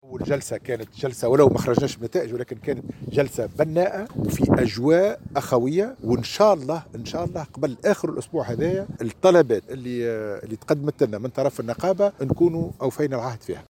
وجاءت هذه التّصريحات على هامش أشغال بناء المركب الثّقافي والرياضي والتربوي بمنطقة البحرالأزرق بالدائرة البلدية المرسى.